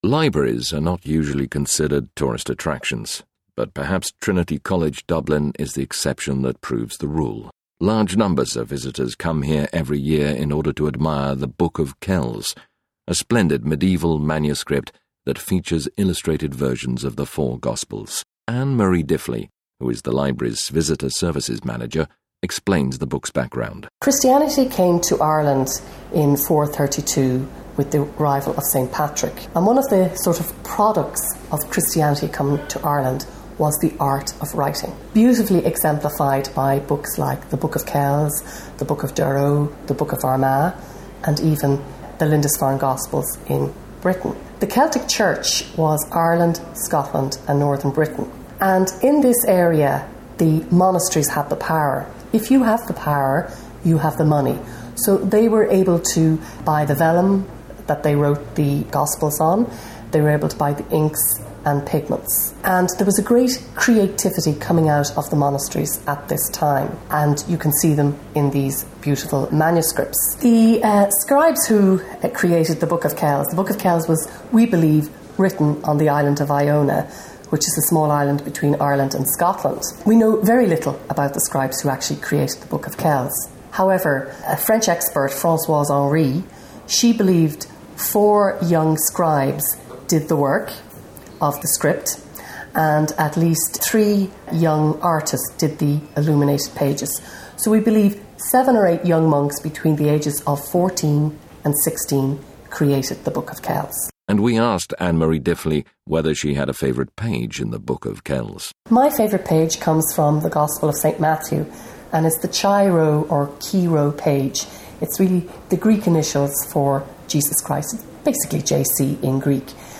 Interview: THE SPARK OF GENIUS